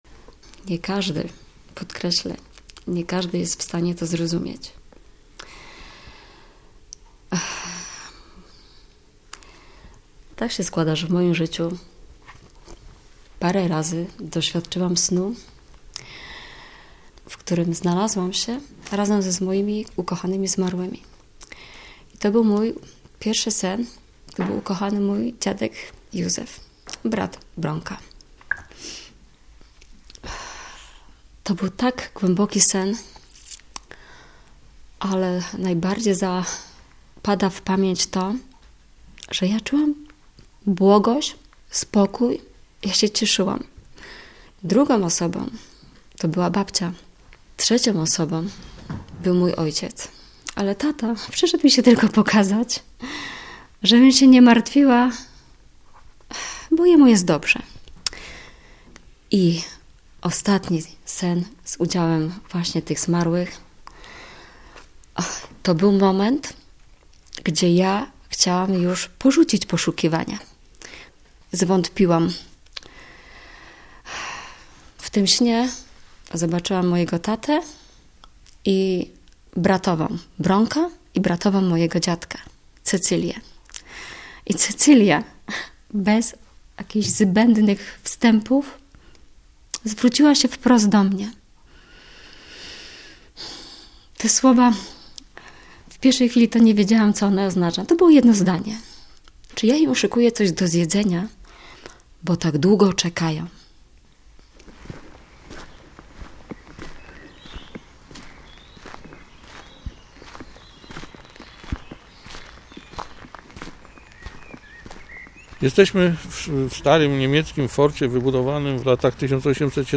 O sile pamięci w reportażu